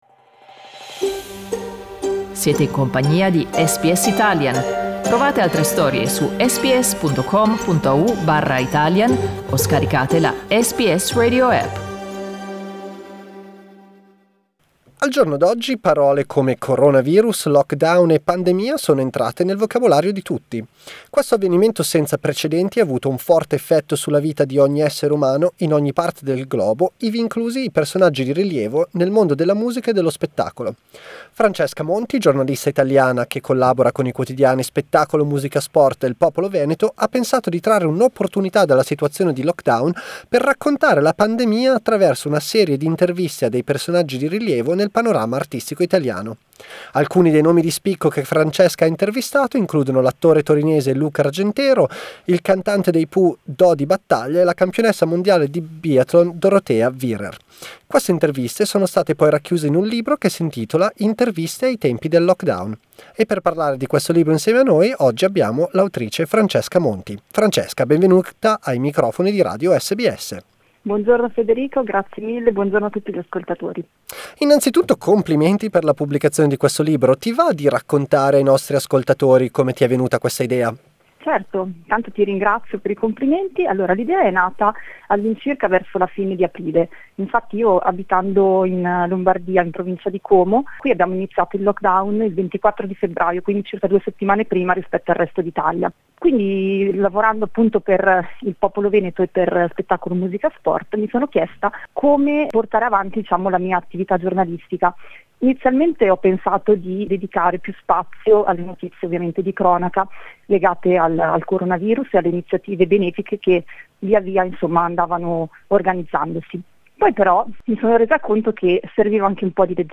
Ascoltate la sua intervista con SBS Italian.